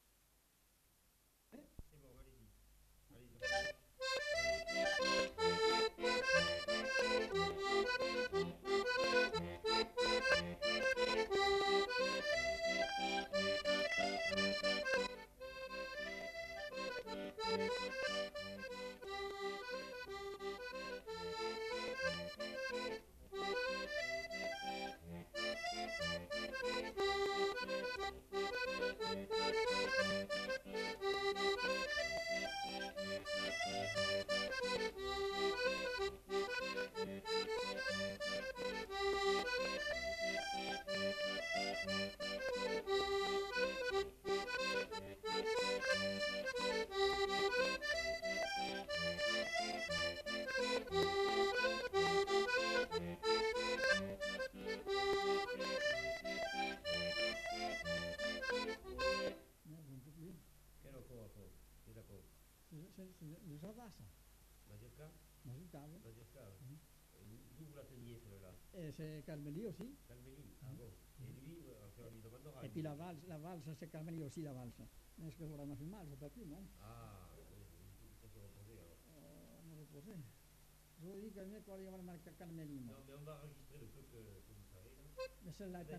Répertoire d'airs à danser de la région de Monteton interprété à l'accordéon diatonique
enquêtes sonores